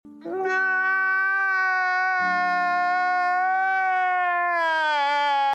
NO Crying meme They said sound effects free download